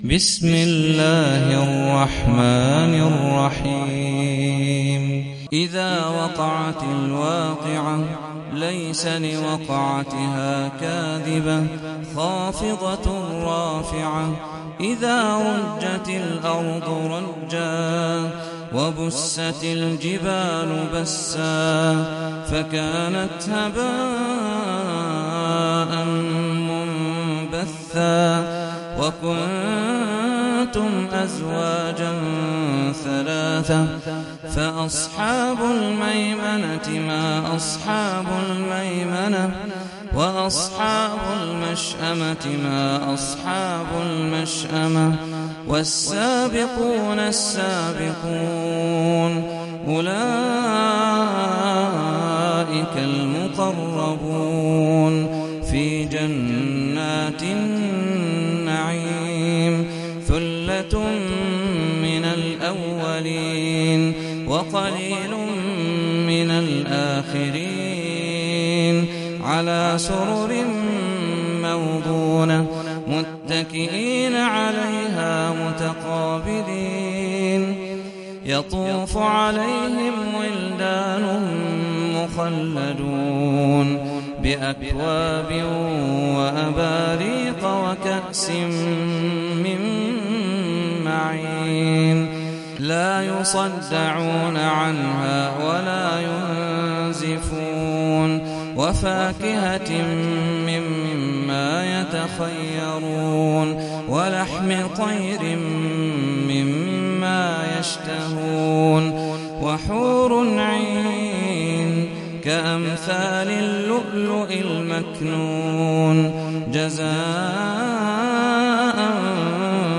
Sûrat Al-Waqi - صلاة التراويح 1446 هـ